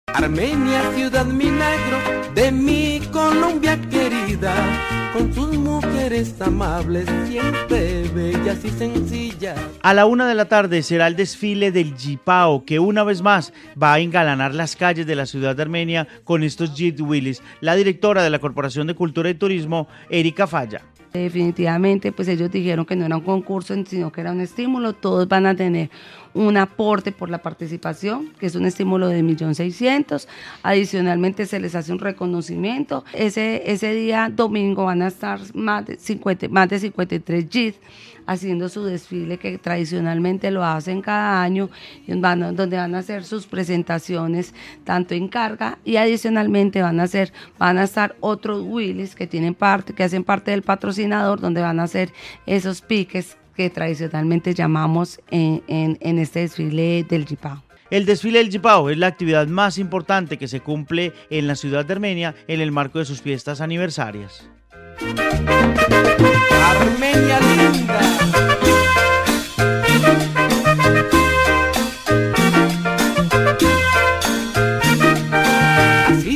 Informe Yipao en Armenia